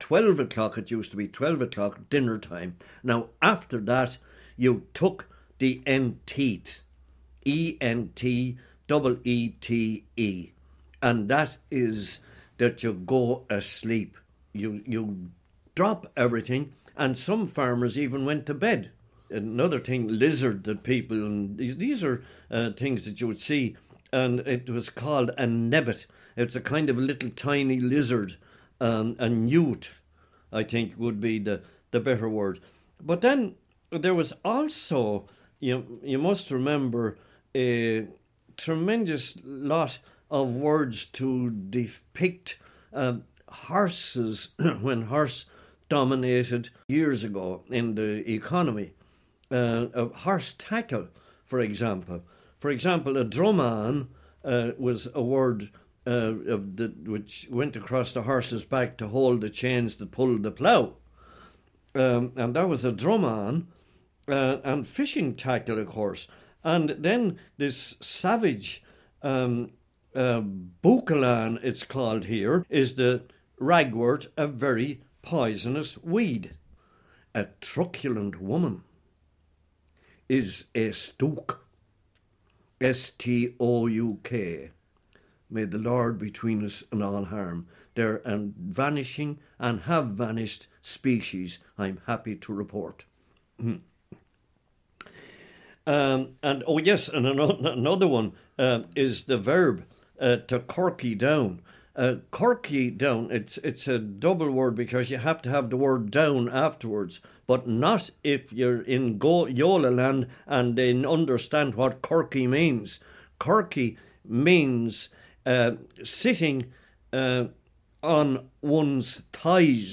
Audio clip from the free Norman Way GPS audio tour: